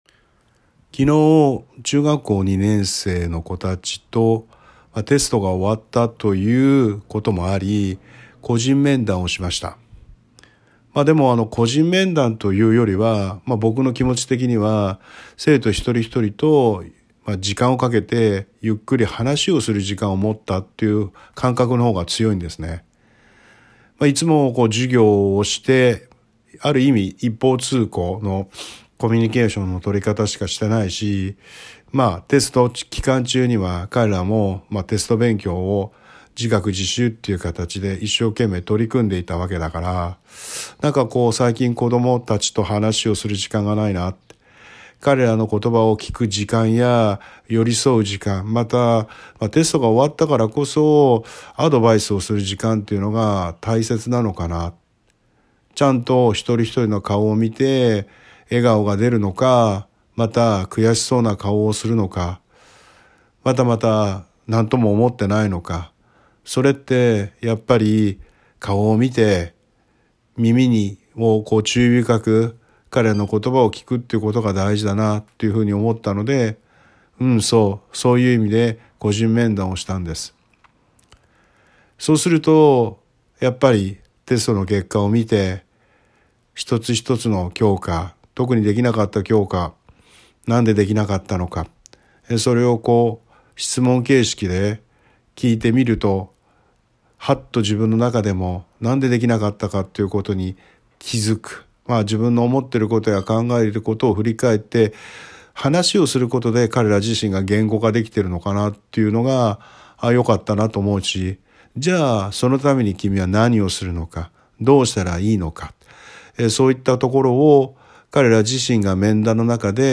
今朝歩きながら、昨日の生徒たちと個人面談した時に感じたことを言語化しておこうと思いiPhoneのボイスメモで録音した。